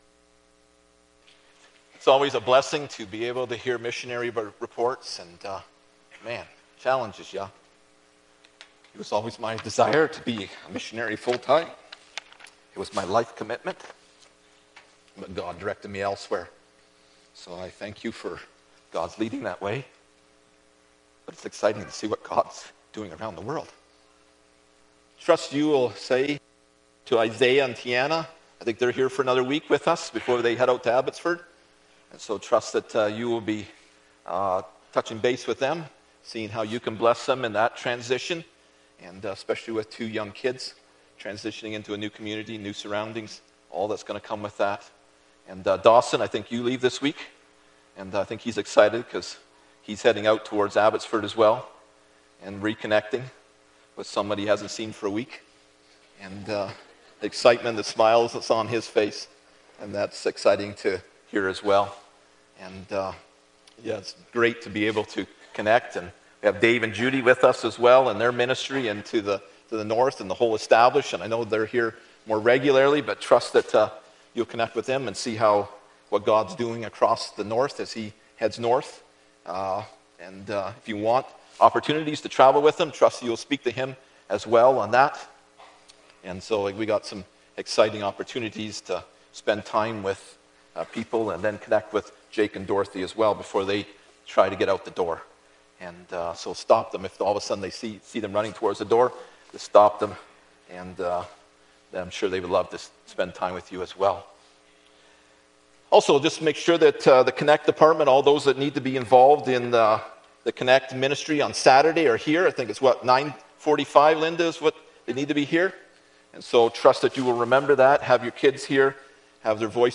Isaiah 6:1-8 Service Type: Sunday Morning Bible Text